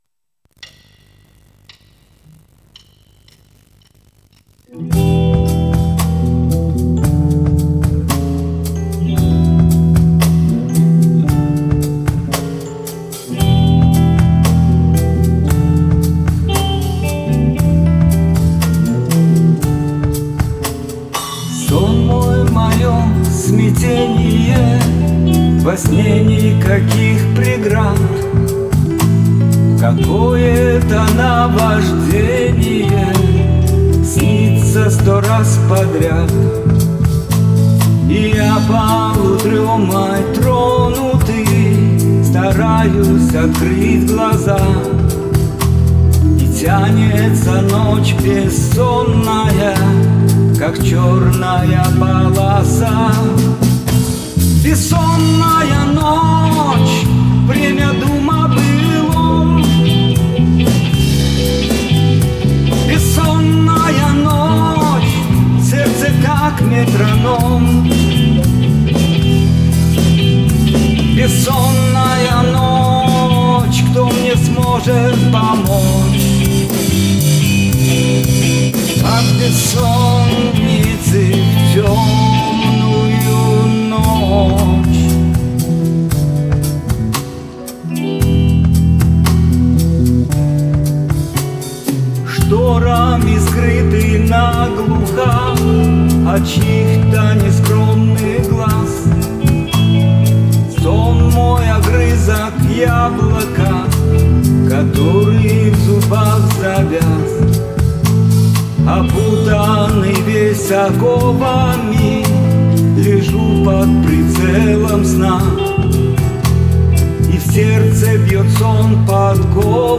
Репетиция.